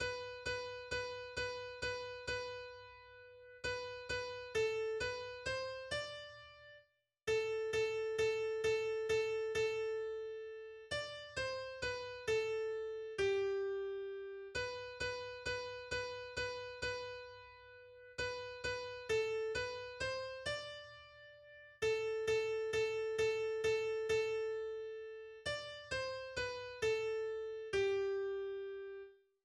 Mittelalterlicher Rundgesang, aus 1609 überliefert.